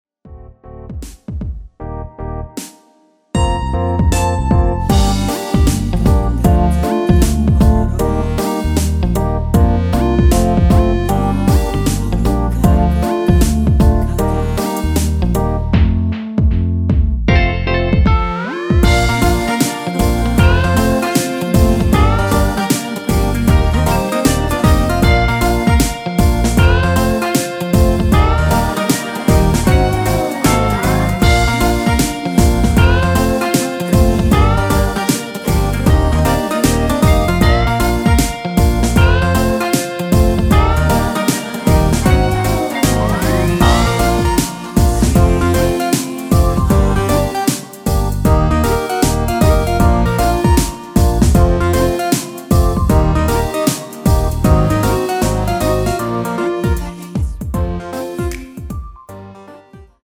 원키 코러스 포함된 MR입니다.
Bb
앞부분30초, 뒷부분30초씩 편집해서 올려 드리고 있습니다.
중간에 음이 끈어지고 다시 나오는 이유는